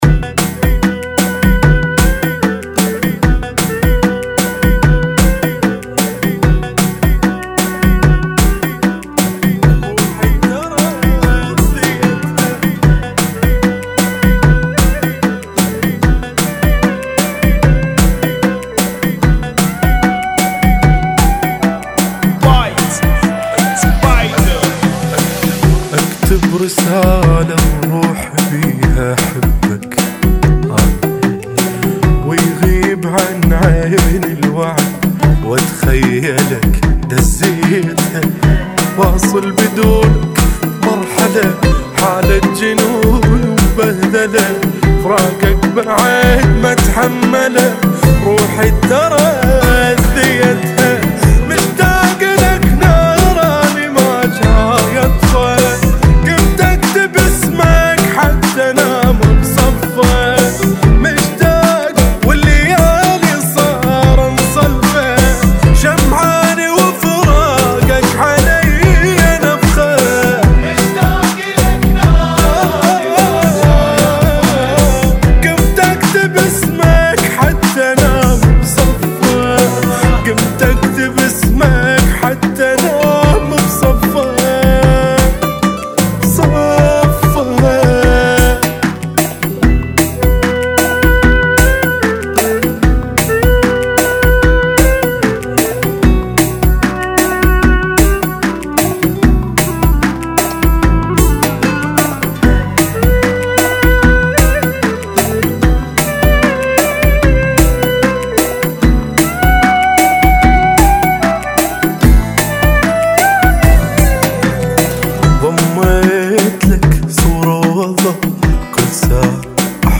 75 Bpm